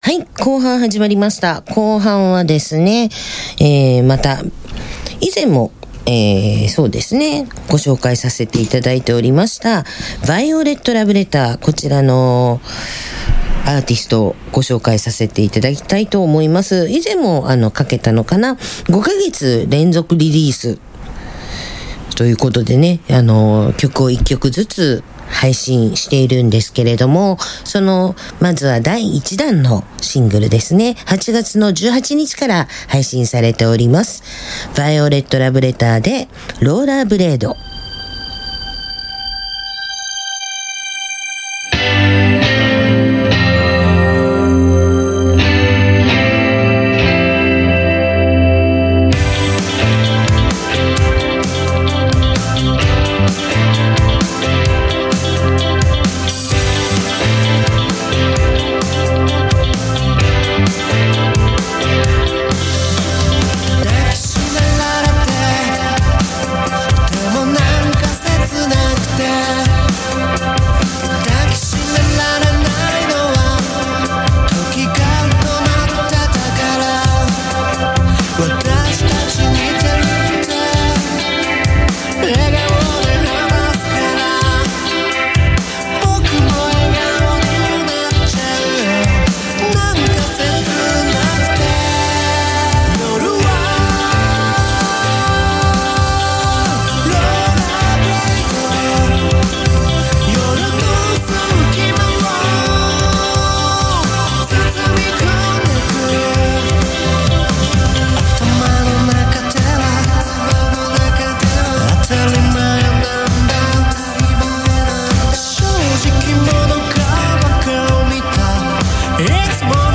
Opning&Ending Music